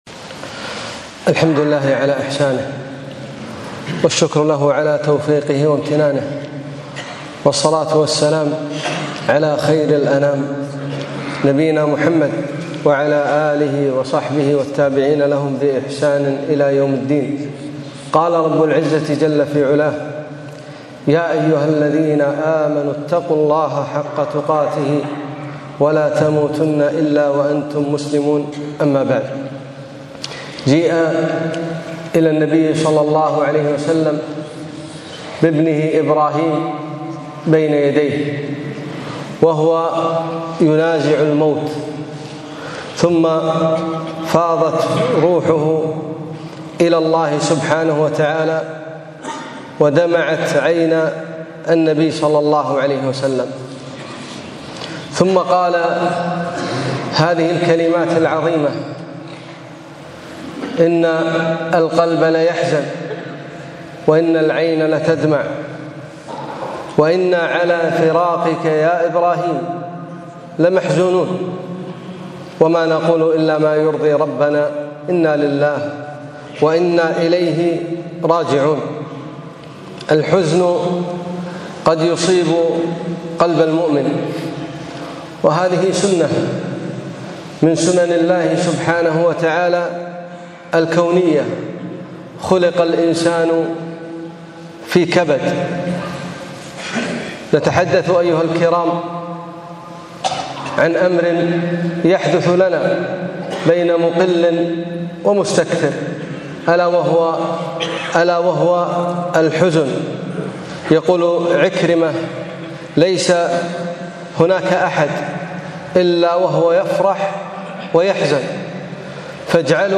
خطبة - الحزن